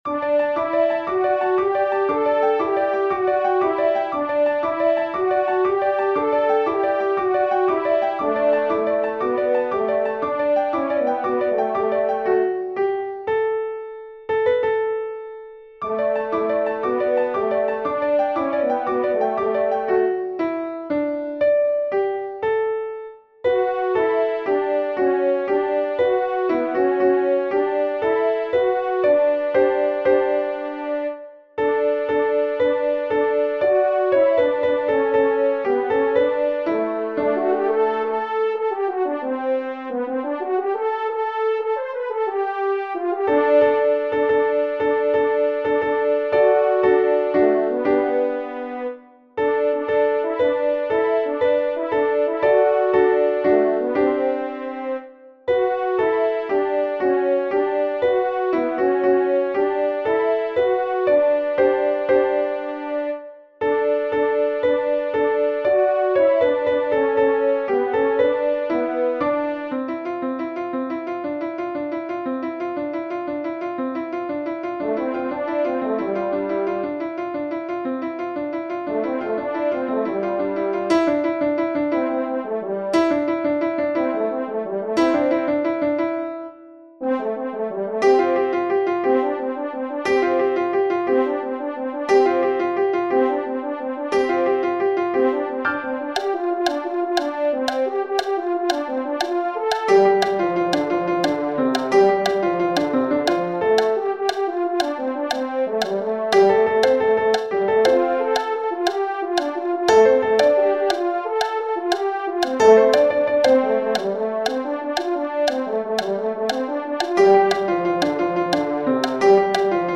These are loud. Turn your sound down before starting a recording.
Alto Practice:
Alto part sounds like a piano. The clicking noise is the choir clapping.
alleluia_by_basler-alto.mp3